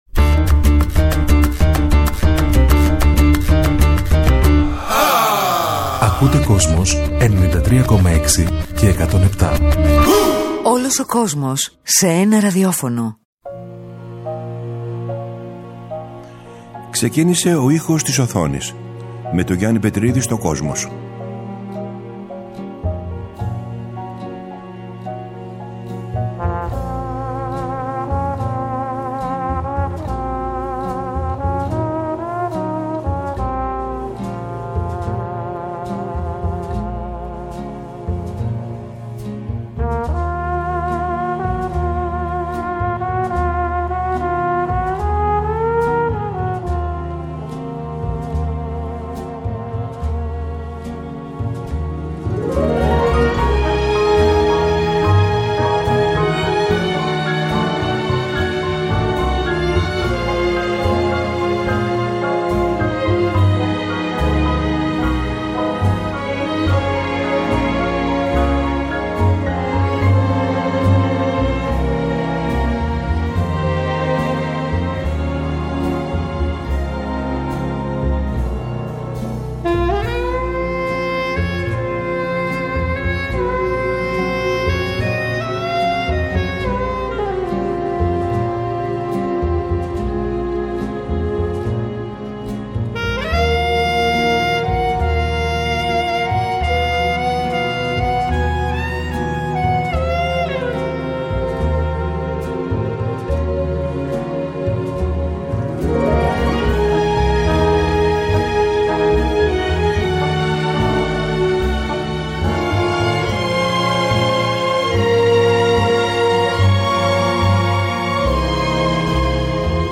Στις εκπομπές αυτές θα ακουστούν μουσικές που έχουν γράψει μεγάλοι συνθέτες για τον κινηματογράφο, αλλά και τραγούδια που γράφτηκαν ειδικά για ταινίες.